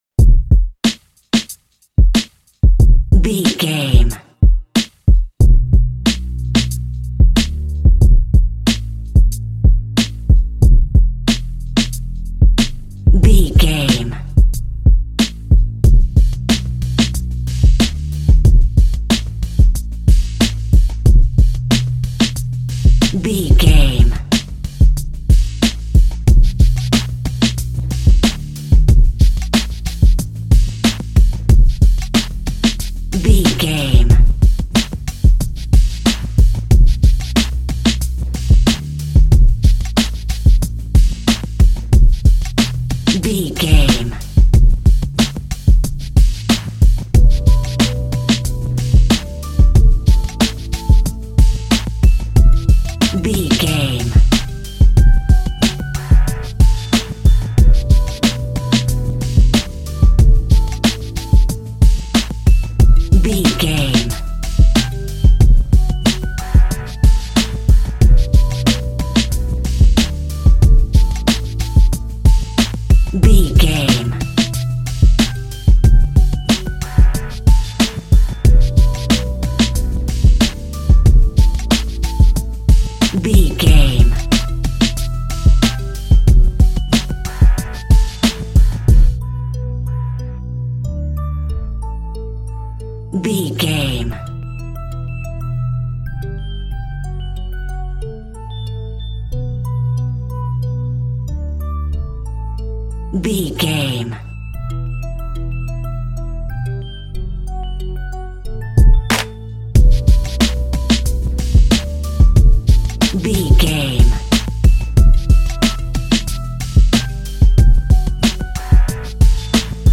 Aeolian/Minor
DOES THIS CLIP CONTAINS LYRICS OR HUMAN VOICE?
WHAT’S THE TEMPO OF THE CLIP?
drum machine
synthesiser
electric piano
hip hop
Funk
neo soul
acid jazz
energetic
bouncy
funky